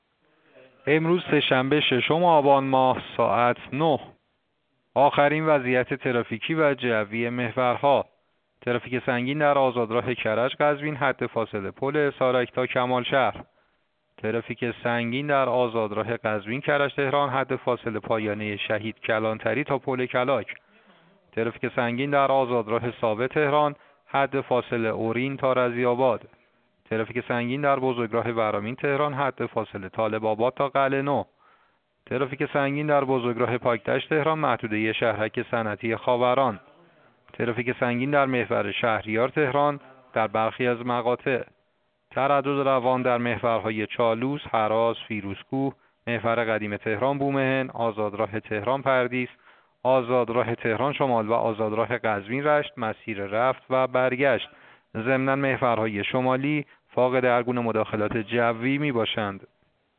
گزارش رادیو اینترنتی از آخرین وضعیت ترافیکی جاده‌ها ساعت ۹ ششم آبان؛